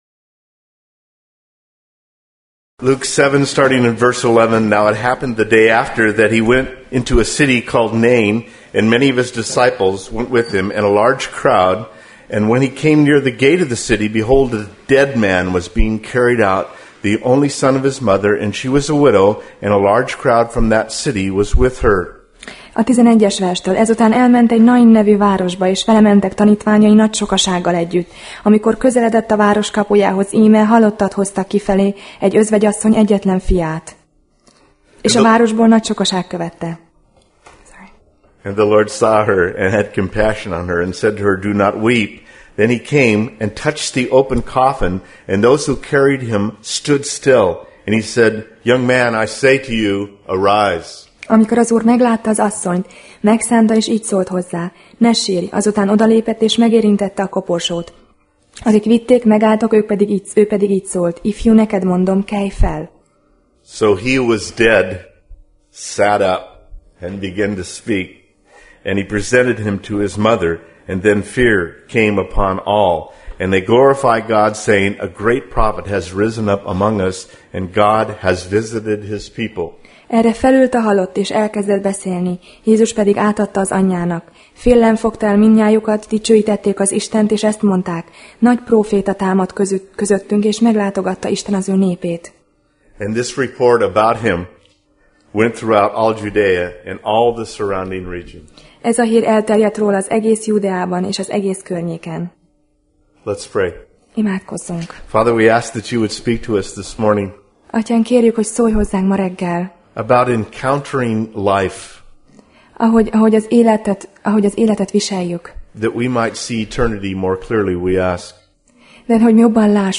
Tematikus tanítás
Alkalom: Vasárnap Reggel